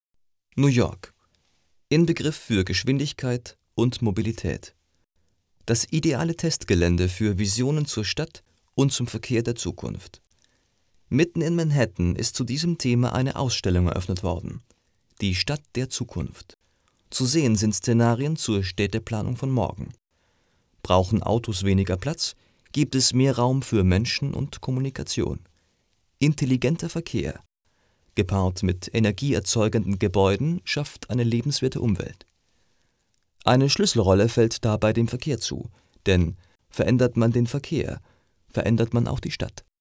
Sprecher für Werbung, Off, Industrie, eLearning,
Sprechprobe: Industrie (Muttersprache):